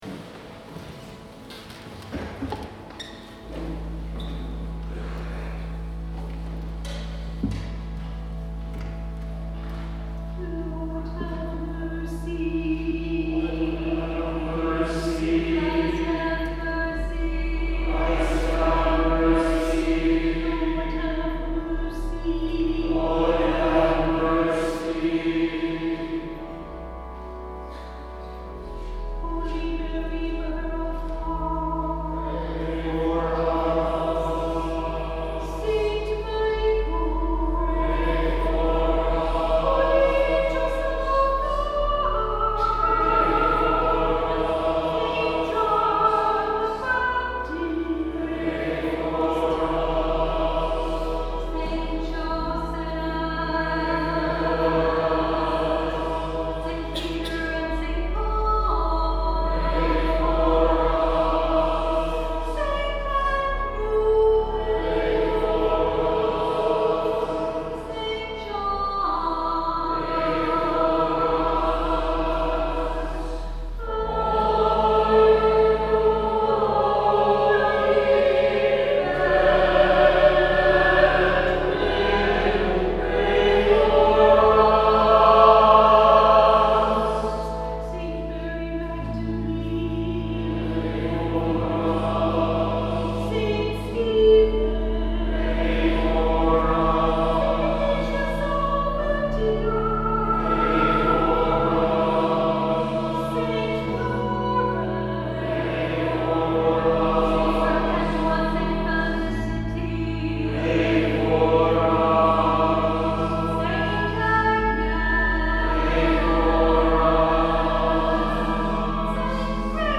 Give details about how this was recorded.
2023 Easter Vigil